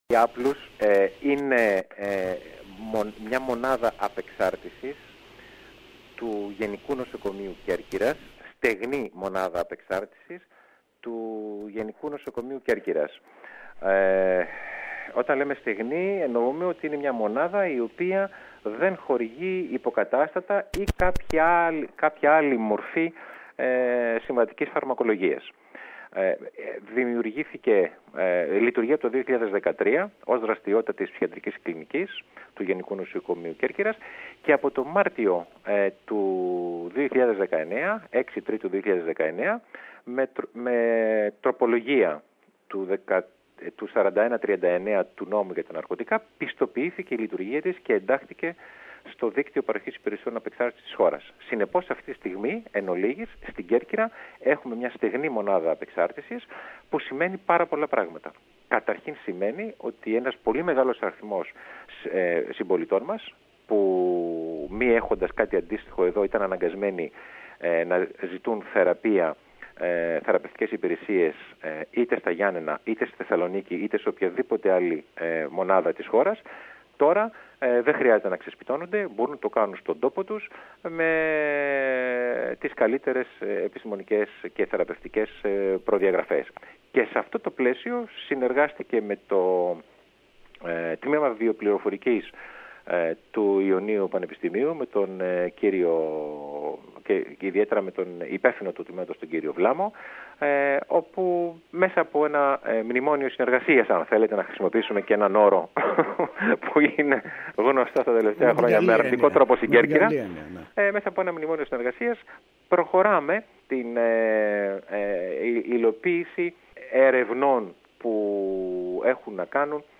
μίλησε το πρωί στην ΕΡΤ Κέρκυρας, δίδοντας λεπτομέρειες της συνεργασίας αυτής.